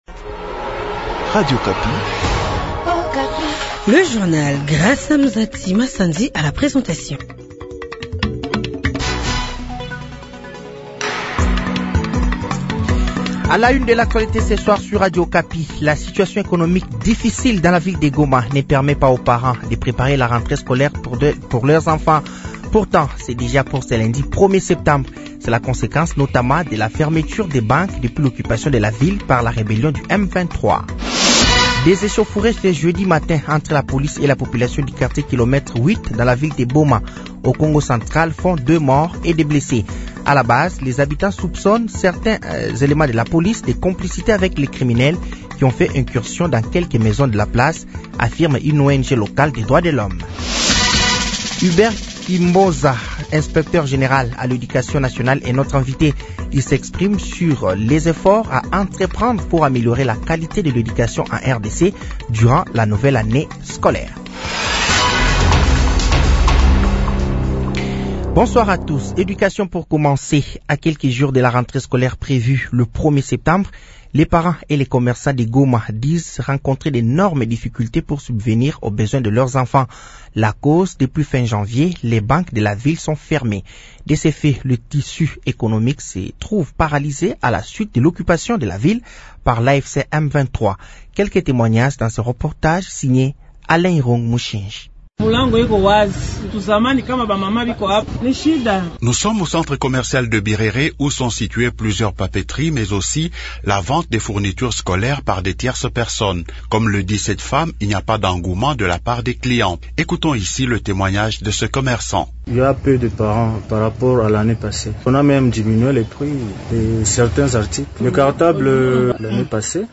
Journal français de 18h de ce jeudi 28 août 2025